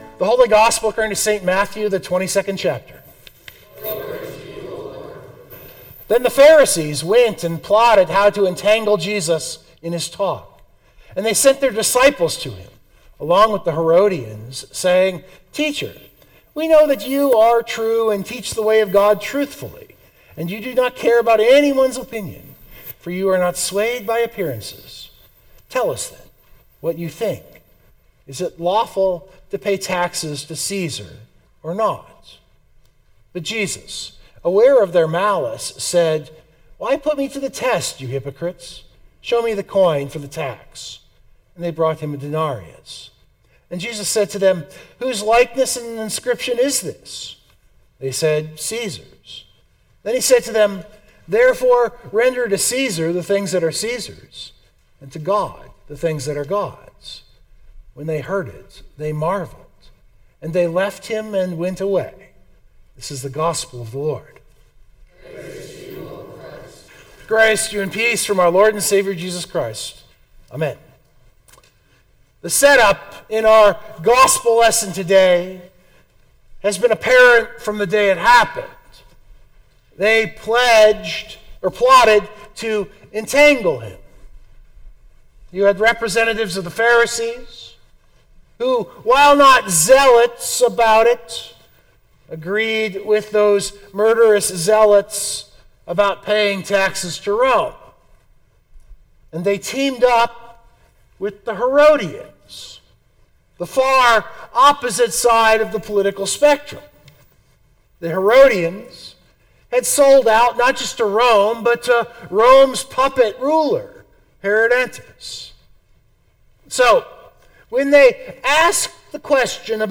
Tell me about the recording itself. Worship Note: I moved our Hymn of the Day after the Sermon in the recording.